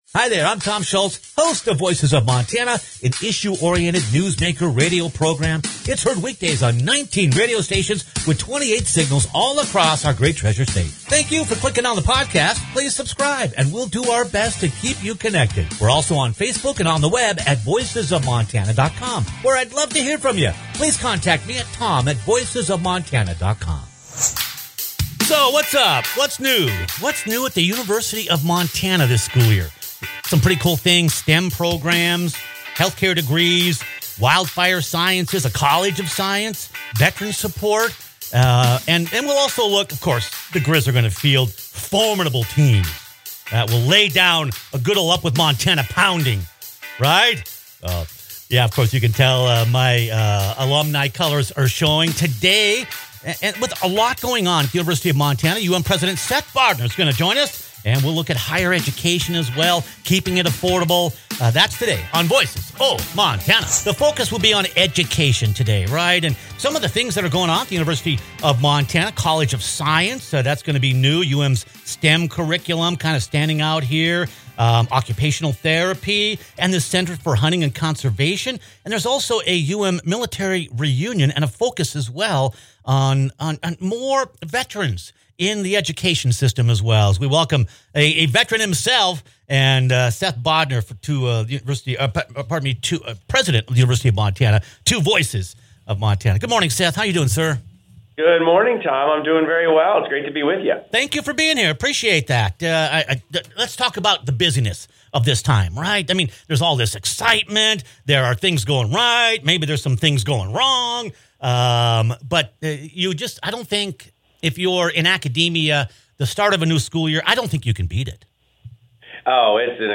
At the University of Montana, enrollment is climbing, research is expanding, and new programs are reshaping the future of the workforce. Click on the podcast for a Voices of Montana conversation with UM President Seth Bodnar.